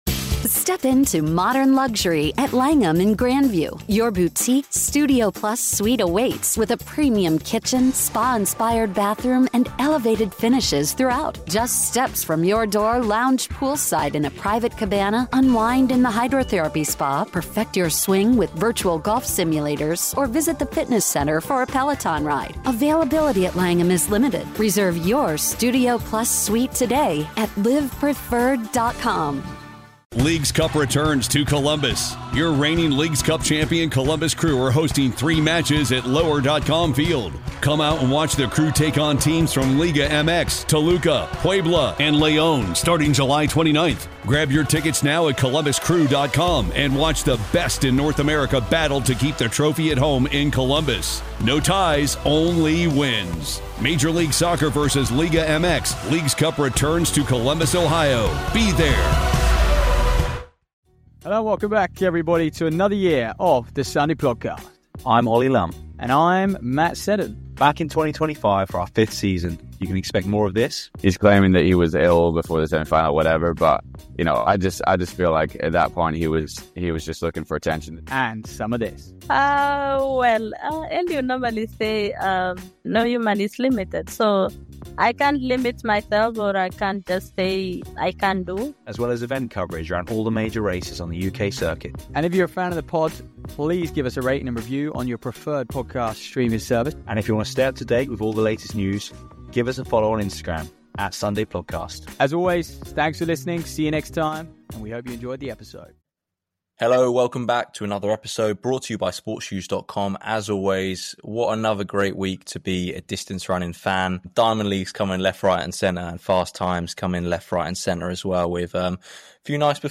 The Diamond League circuit is producing serious times right now, and the boys debate how these performances might translate to championship performances later in the summer.